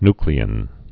(nklē-ĭn, ny-)